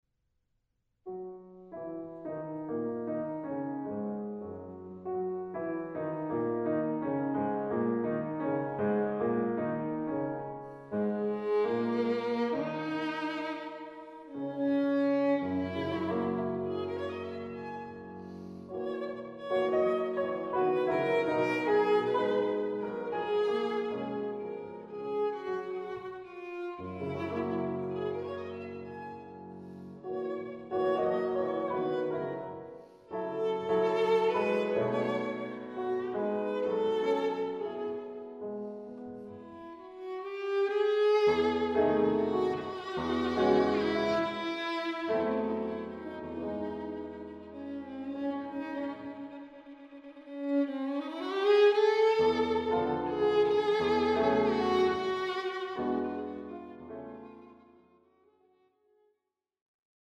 violino
Registrazione: 2 e 3 marzo 2012, Sala Streicher, Salisburgo.